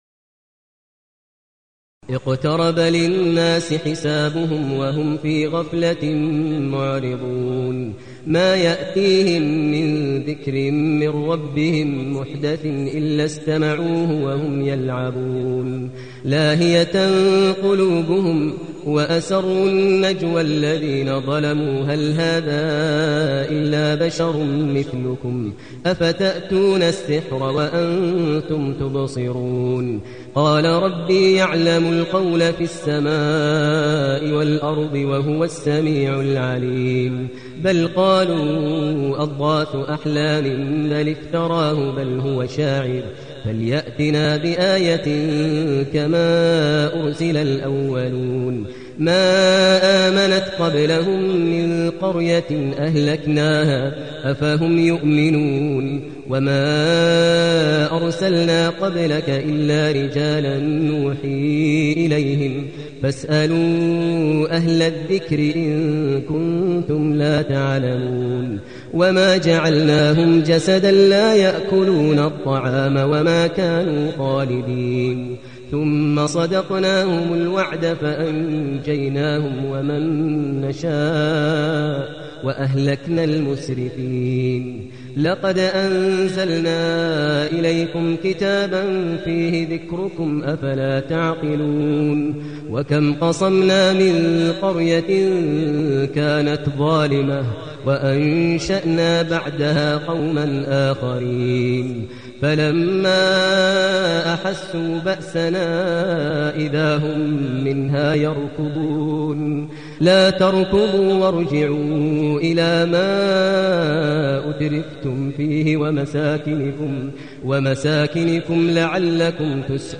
المكان: المسجد النبوي الشيخ: فضيلة الشيخ ماهر المعيقلي فضيلة الشيخ ماهر المعيقلي الأنبياء